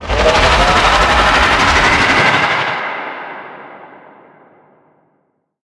Sfx_creature_iceworm_vo_run_01.ogg